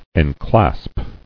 [en·clasp]